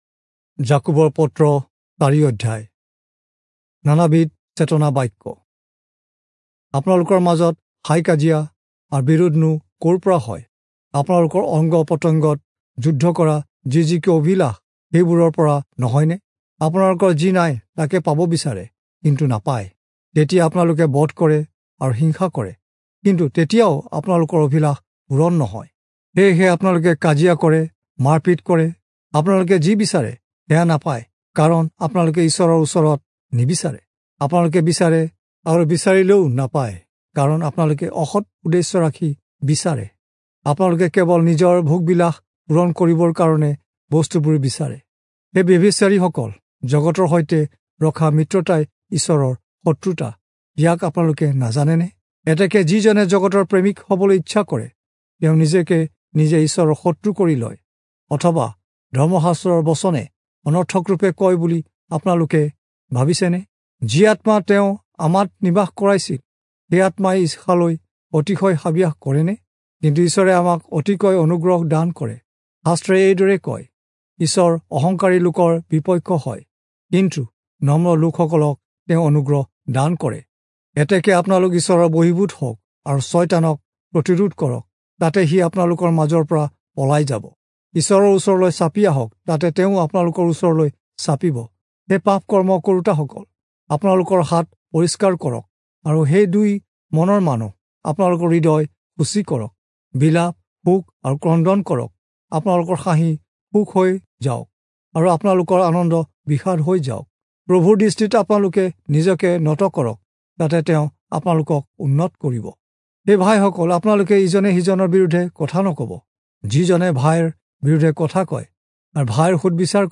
Assamese Audio Bible - James 5 in Knv bible version